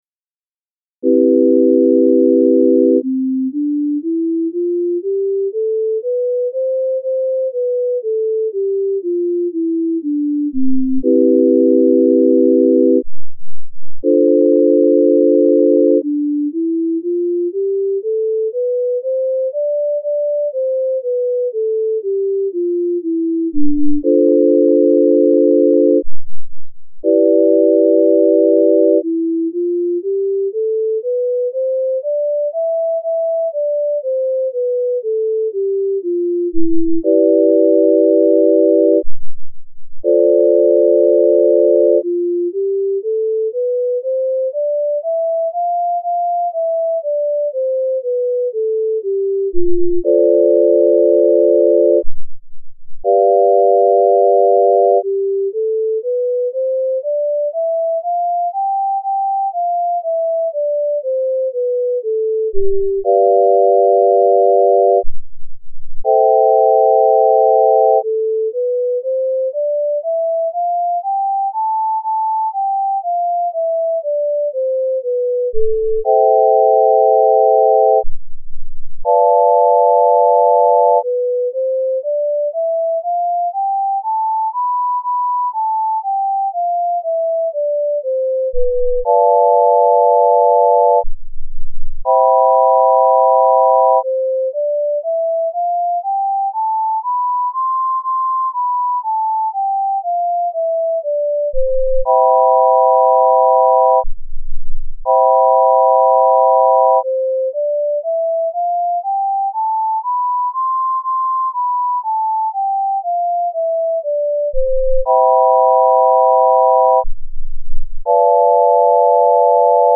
0001-C-Major Scale Using the Even Temperment Scale
C-Major Scale Using the Tempered Scale